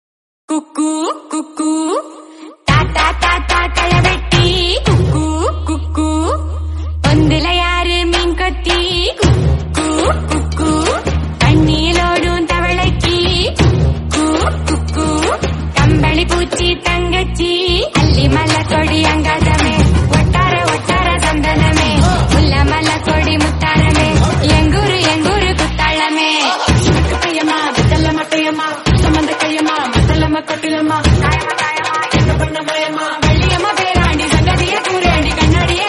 Bgm ringtone free download